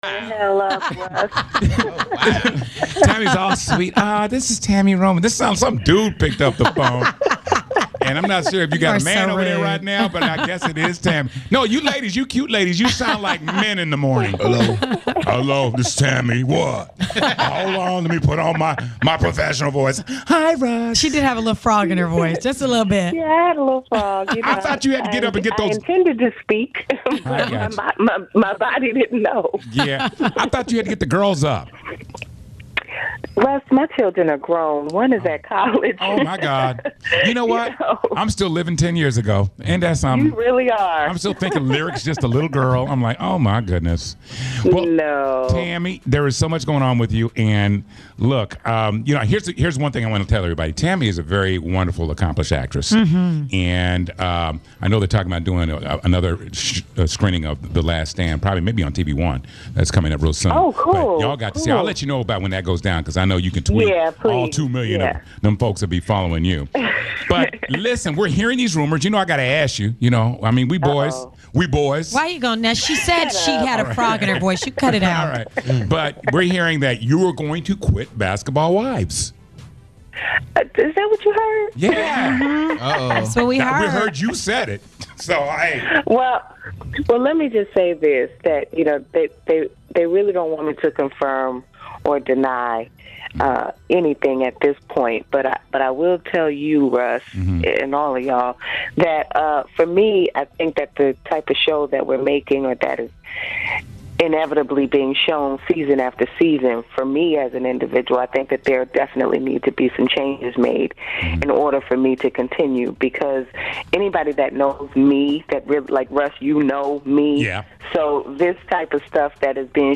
9-18-13-tami-roman-interview.mp3